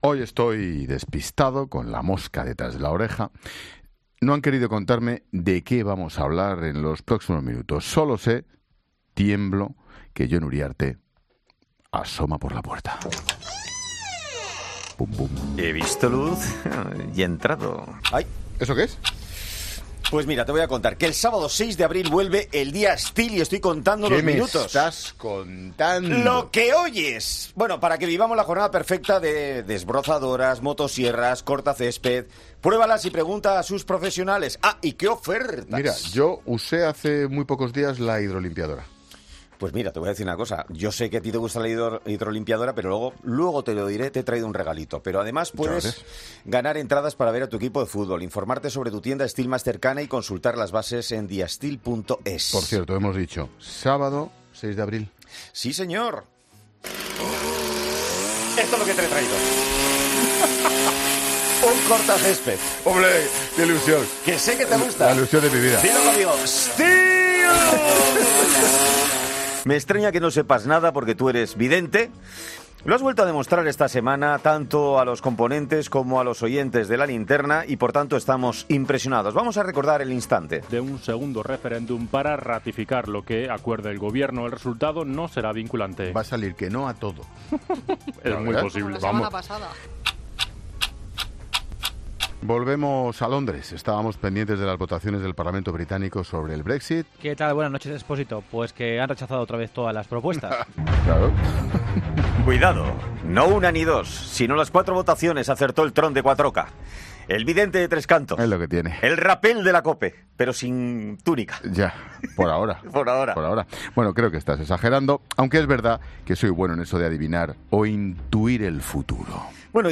AUDIO: Jon Uriarte analiza con Ángel Expósito una de las noticias más importantes y destacadas del día, pero a través de su particular visión de las cosas